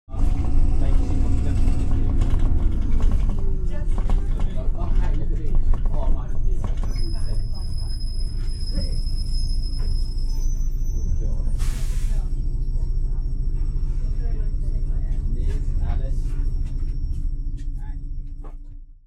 جلوه های صوتی
دانلود صدای اتوبوس 1 از ساعد نیوز با لینک مستقیم و کیفیت بالا